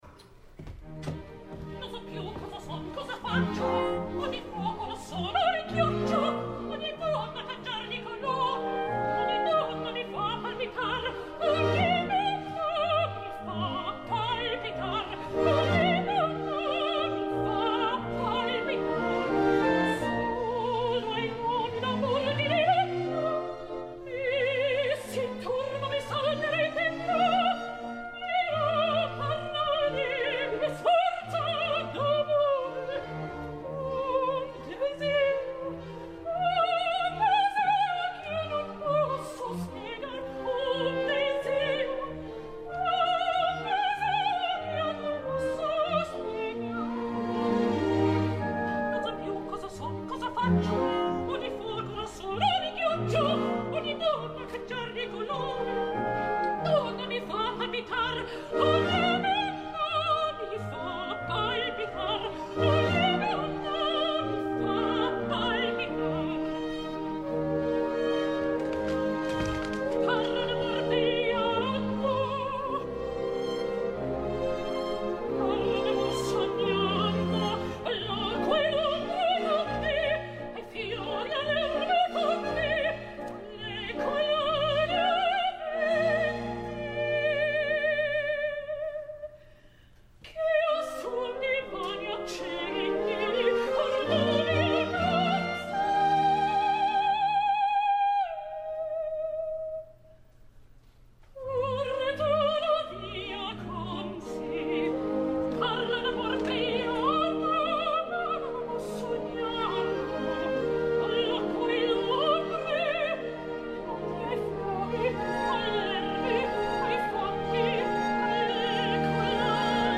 Cherubino……………………Joyce DiDonato
Lyric Opera of Chicago, 28 de febrer de 2010
No sembla està gaire còmoda i té algun problema amb el fiato, segurament el Cherubino és un rol que no farà gaires vegades més. Però no hi ha bona compenetració amb Davis i alguna precipitació.